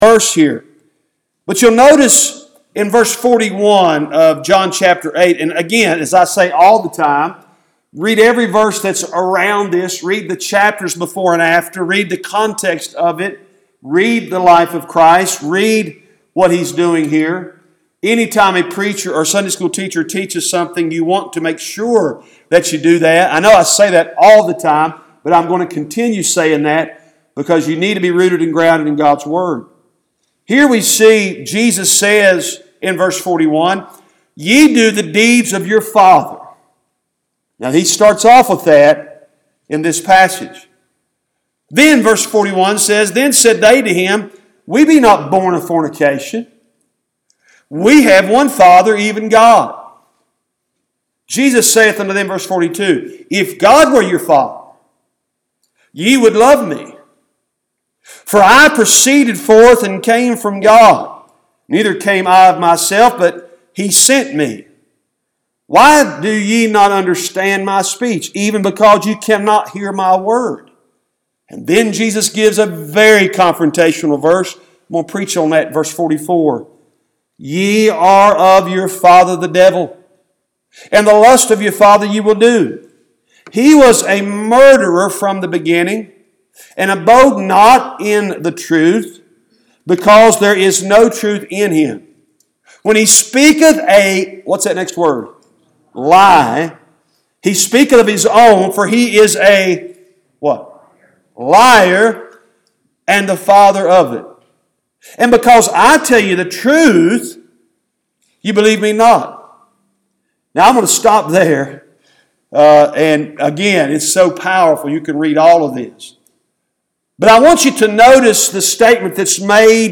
Cooks Chapel Baptist Church Sermons